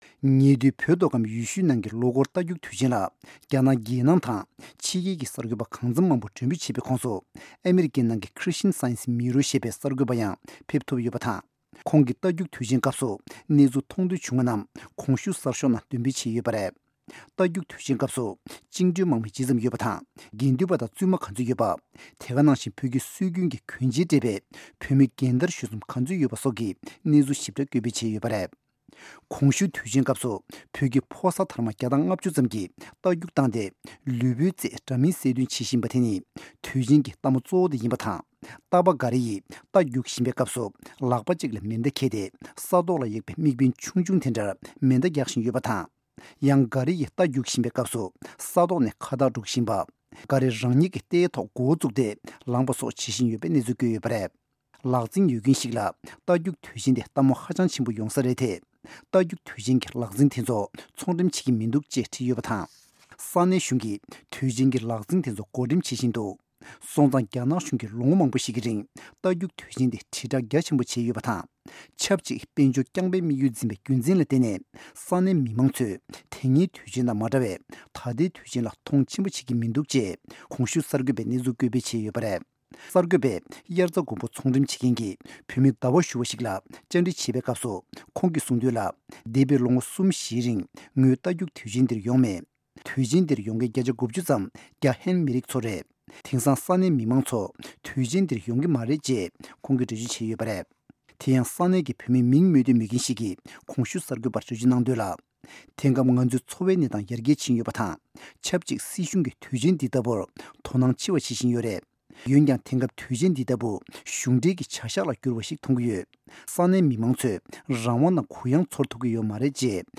གནས་ཚུལ་སྙན་སྒྲོན་